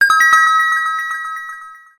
ショートメッセージサウンド。